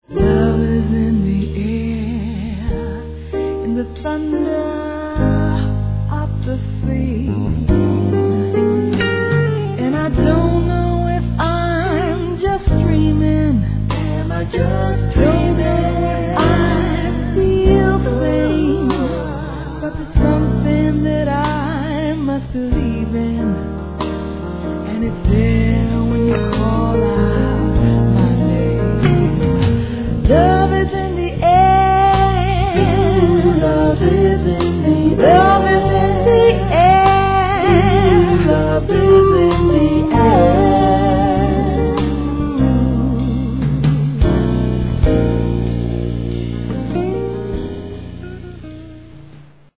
Die Top Ballade NEU produziert